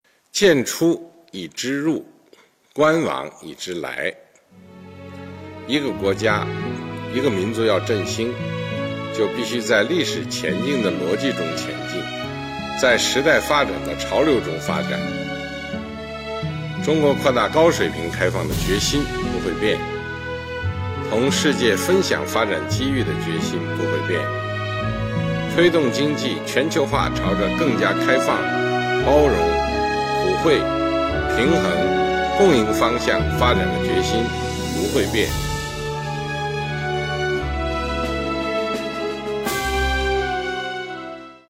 ——在第四届中国国际进口博览会开幕式上的主旨演讲
中华人民共和国主席 习近平